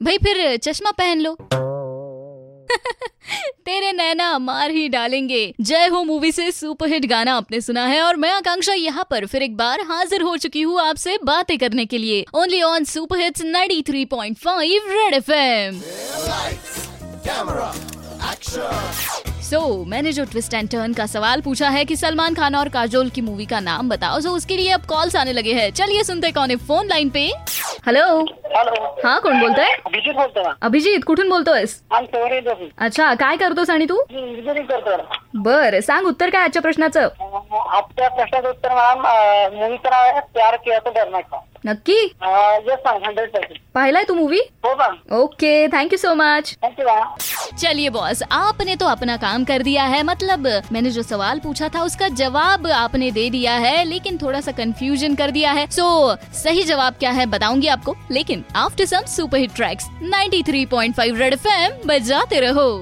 interaction with listeners in twist & turn